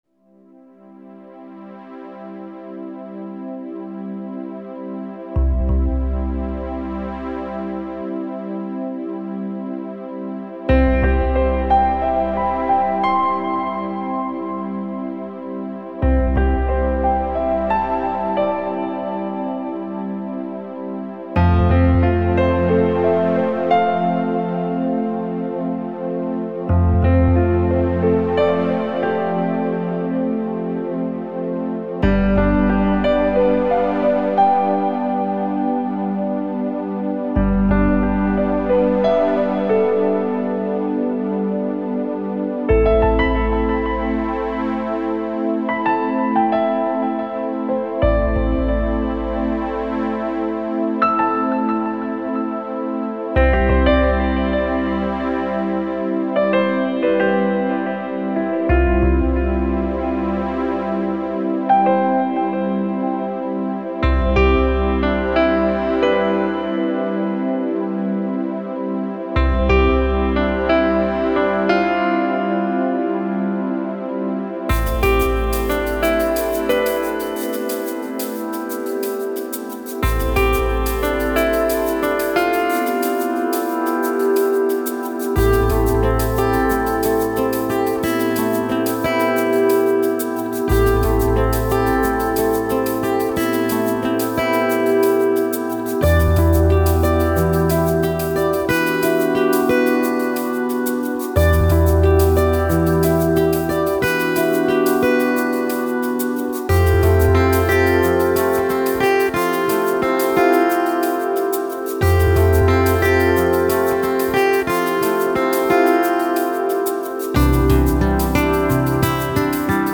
ορχηστρικές συνθέσεις
Lounge & Calm διάθεση